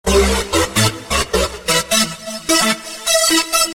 标签： 电子 女声 电子 合成器 实验 循环 电子 人声 技术 另类 渐进
声道立体声